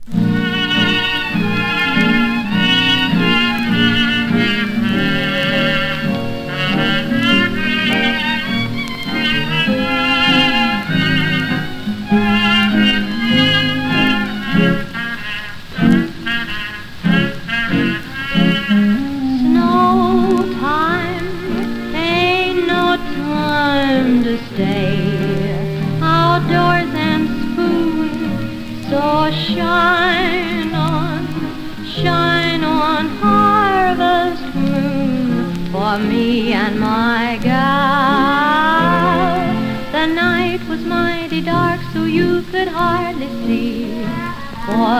Jazz, Stage & Screen, Ragtime　USA　12inchレコード　33rpm　Mono